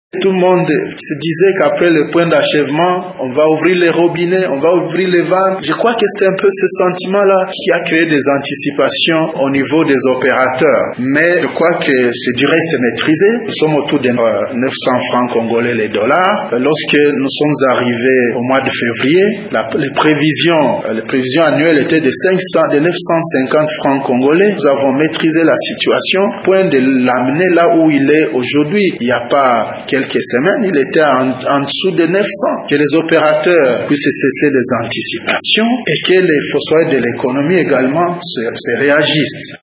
Ci-dessous un extrait de ses propos à la conférence de presse tenu à Kinshasa: